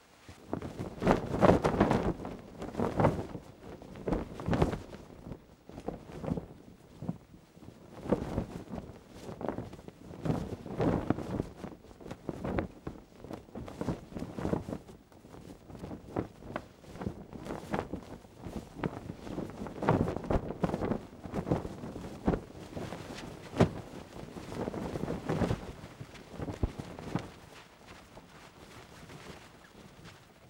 cloth_sail.L.wav